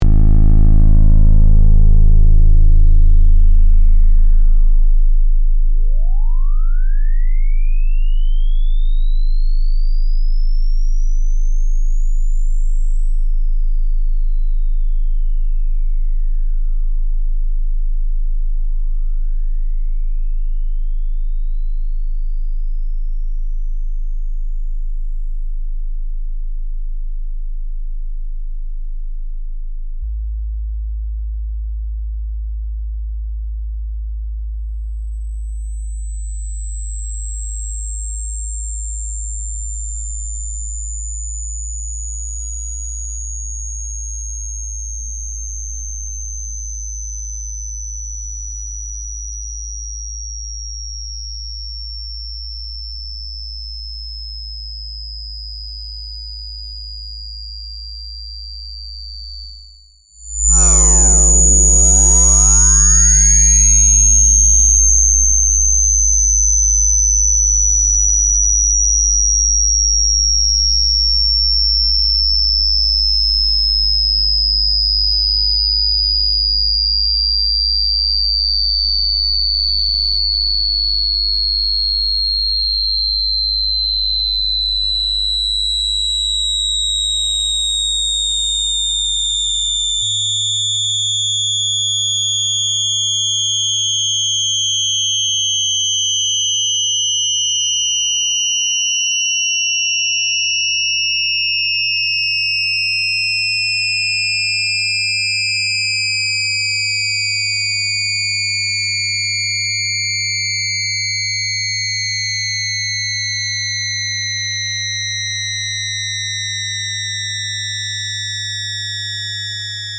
Purgatory V (2005) – prepared piano